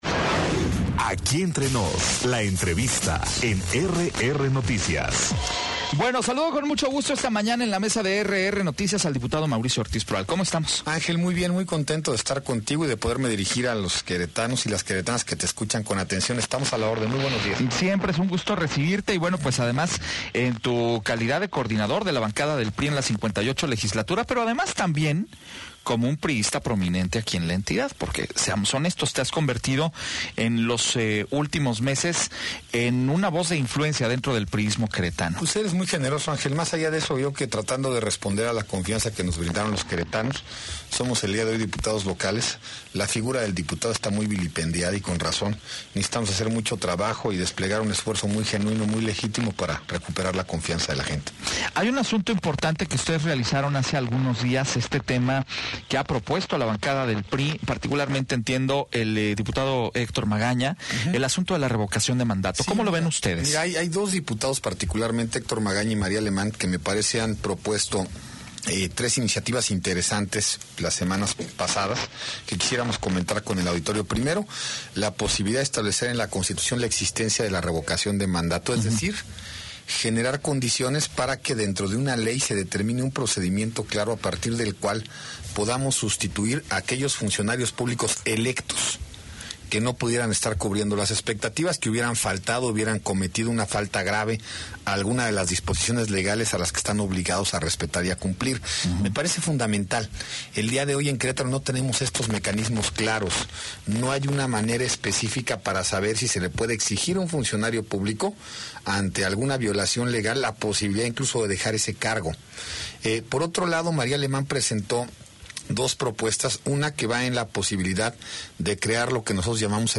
Entrevista con Mauricio Ortiz Proal, diputado local del PRI - RR Noticias